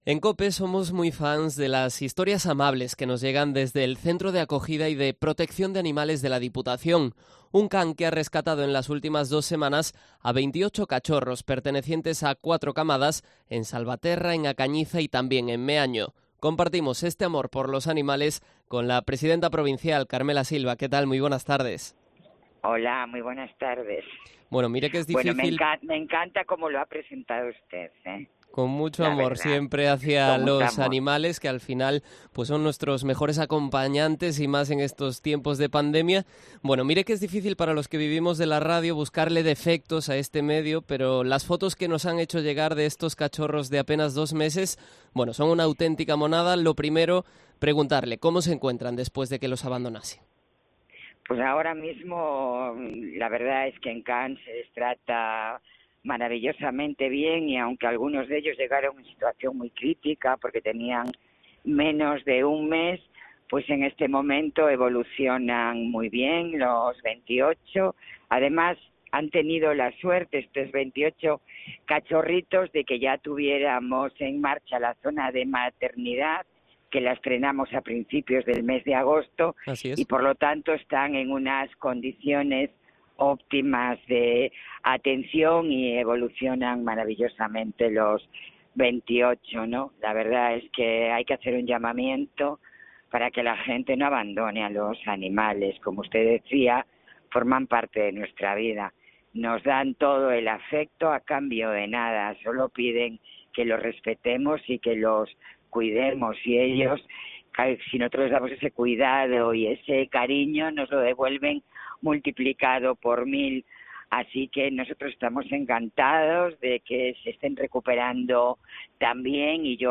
Entrevista a Carmela Silva, presidenta de la Deputación de Pontevedra